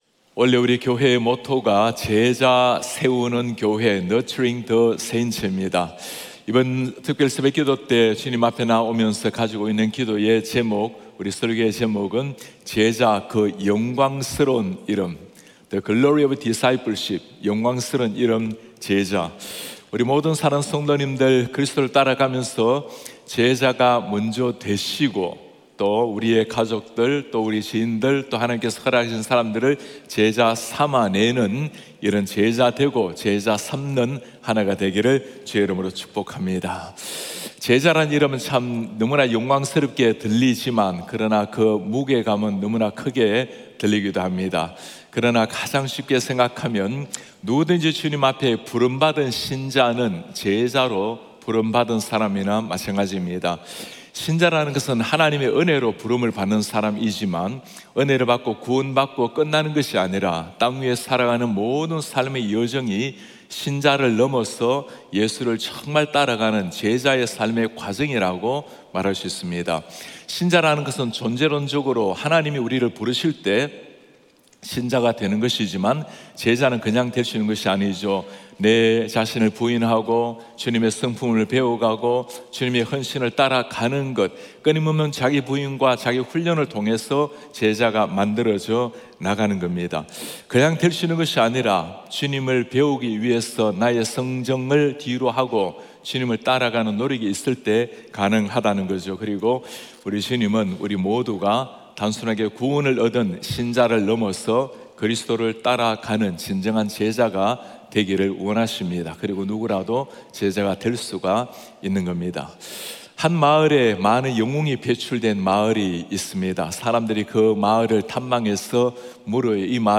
예배: 특별 집회